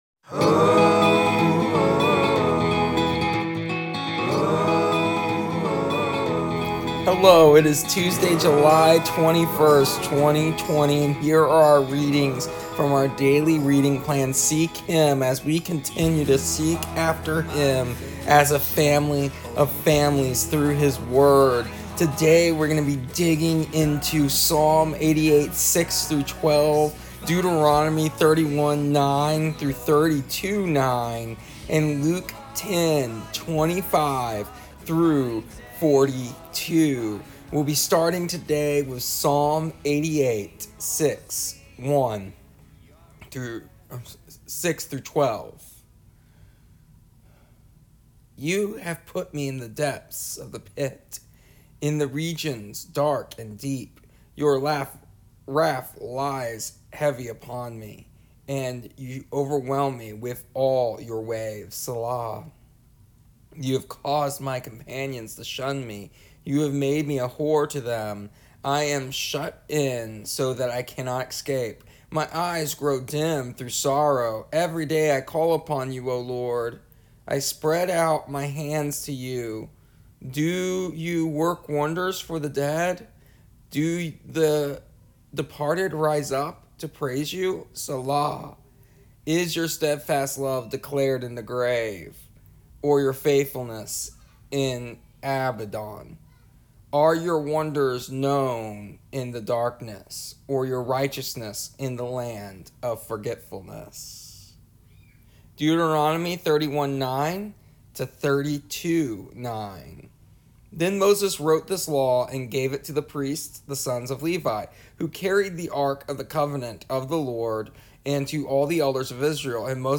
Here is an audio version of our daily readings from our daily reading plan Seek Him for July 21st, 2020.